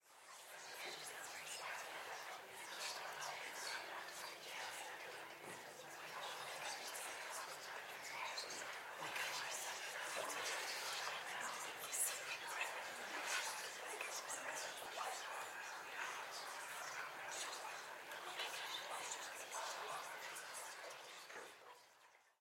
Звук чистого шепота группы людей
zvuk-chistogo-shepota-gruppy-liudei.mp3